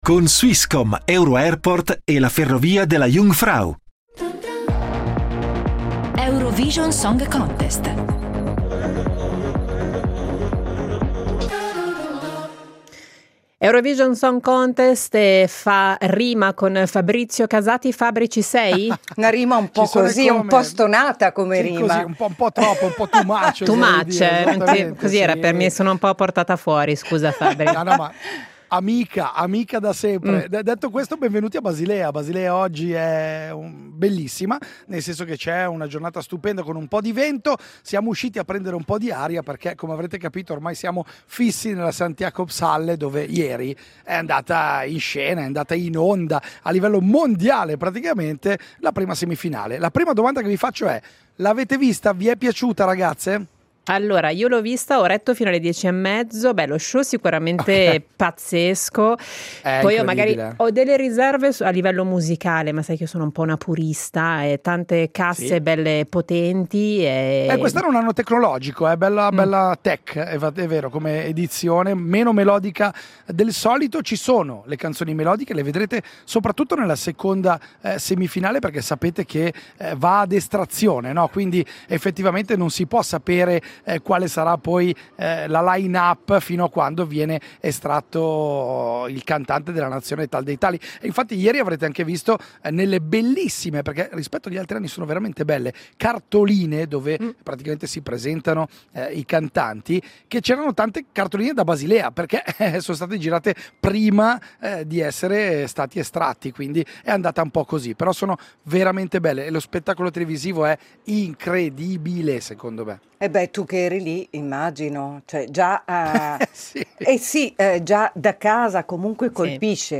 in diretta da Basilea, per commentare la prima semifinale.